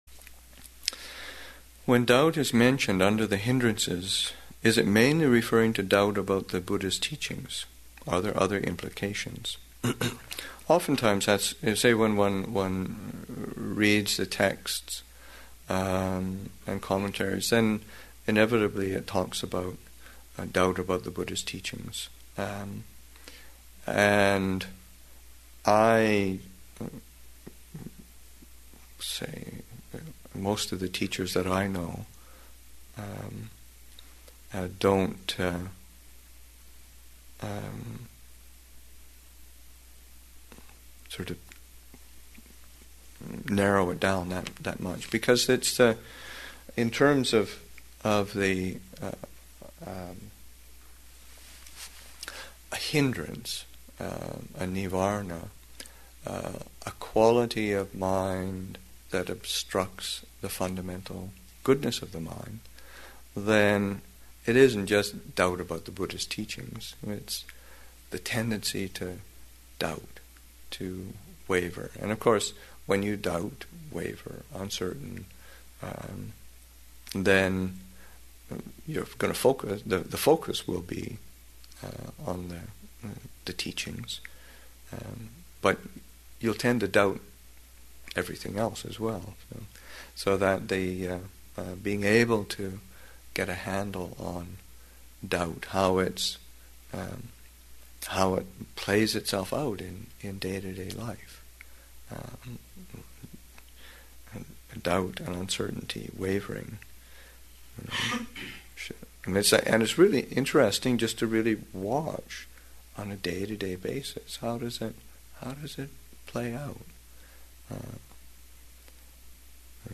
Metta Retreat, Session 3 – Sep. 11, 2008